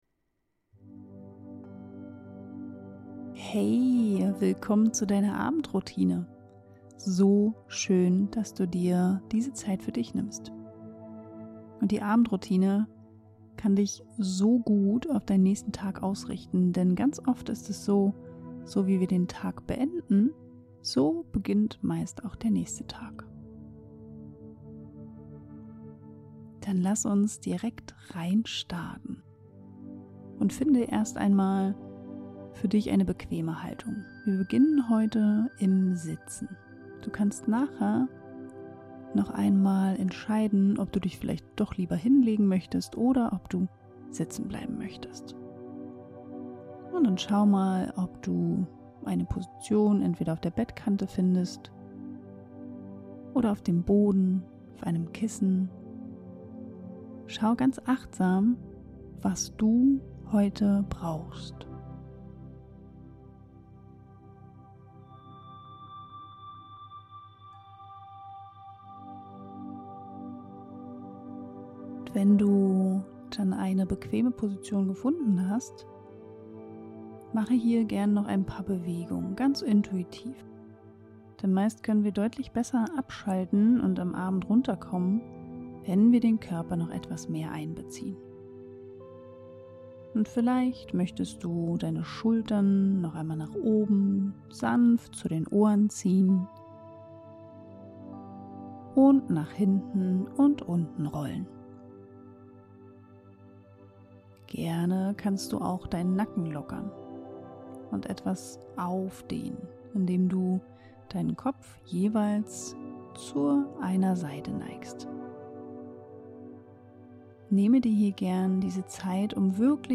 Atemmeditation für einen erholsamen Schlaf ~ Atemgesundheit - Balance statt Hektik Podcast
Dann ist diese liebevolle Abendmeditation genau das Richtige für dich.